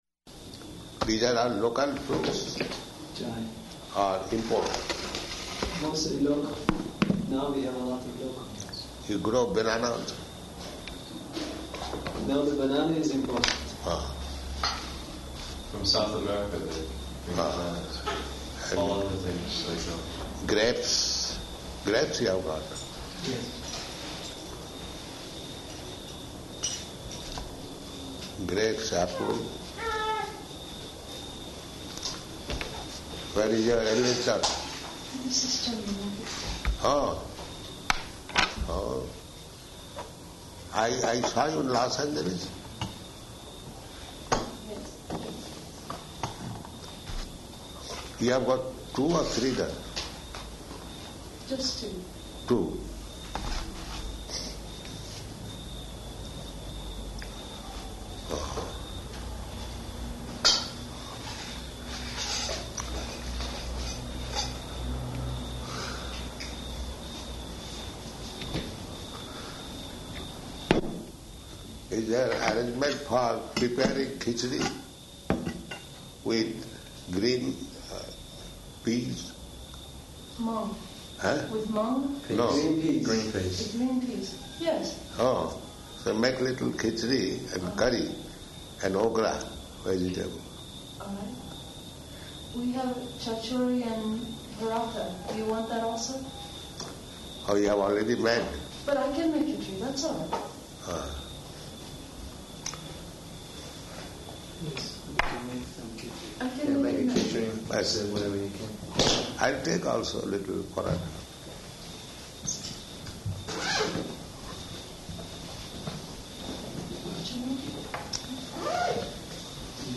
Room Conversation
Room Conversation --:-- --:-- Type: Conversation Dated: August 7th 1976 Location: Tehran Audio file: 760807R1.TEH.mp3 Prabhupāda: These are all local fruits?